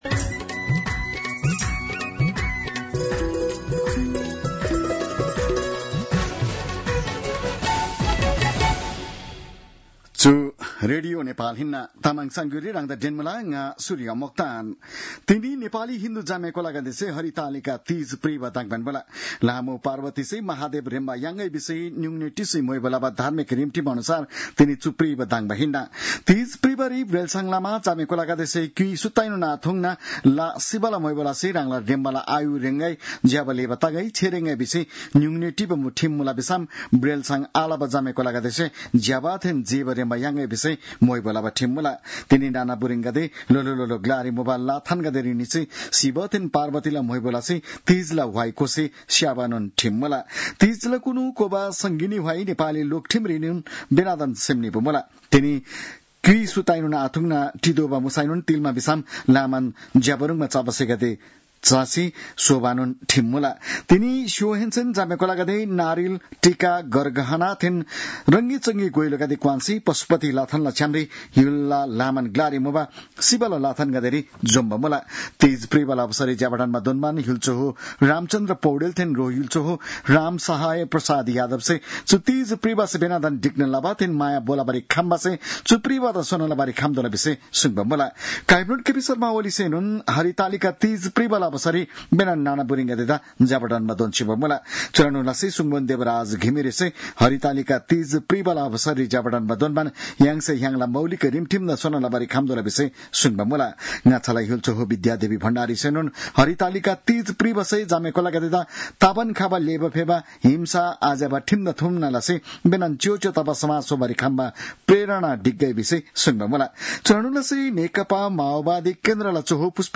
तामाङ भाषाको समाचार : १० भदौ , २०८२